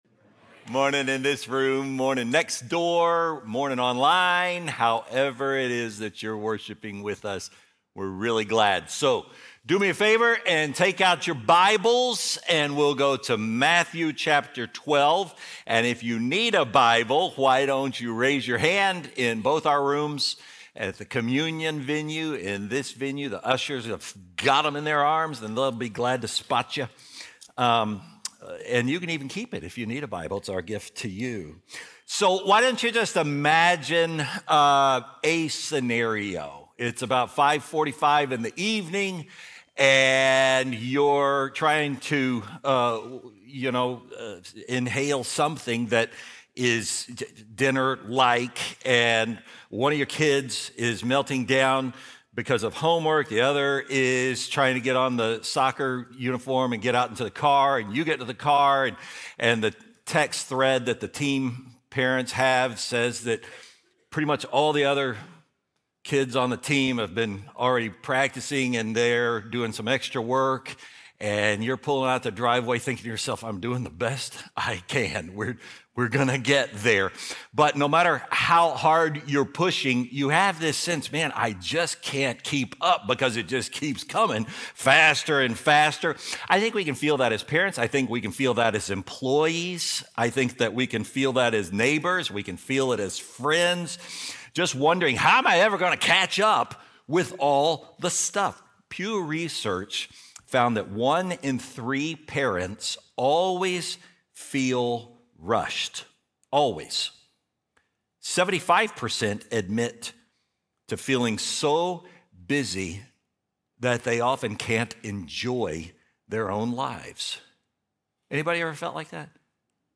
Faithbridge Sermons Lord of the Sabbath Mar 01 2026 | 00:36:05 1x 00:00 / 00:36:05 Subscribe Share Apple Podcasts Spotify Overcast RSS Feed Share Link Embed